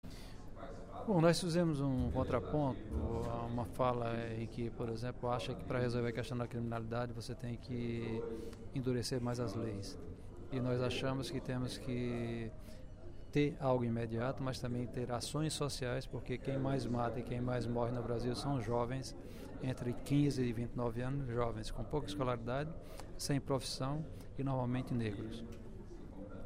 Em pronunciamento durante o primeiro expediente da sessão plenária desta quarta-feira (05/11), o deputado Professor Pinheiro (PT) prestou esclarecimentos acerca do decreto legislativo da presidente Dilma Rousseff que trata dos conselhos de representação populares.